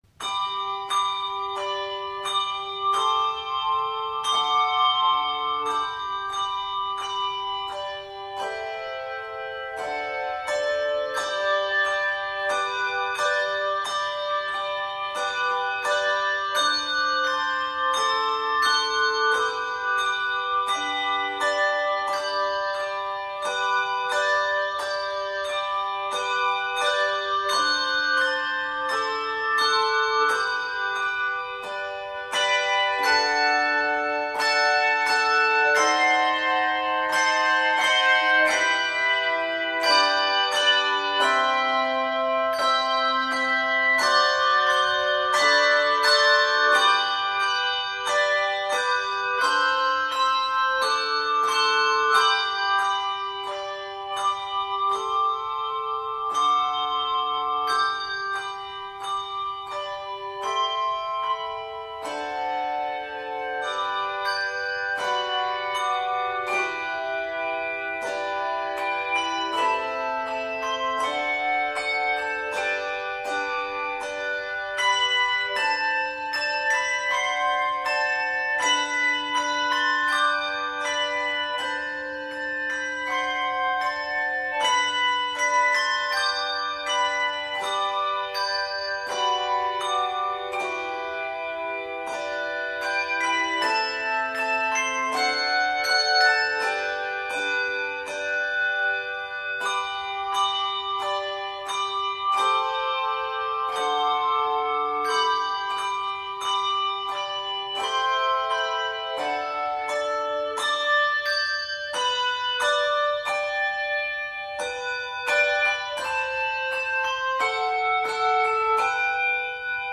Key of G Major.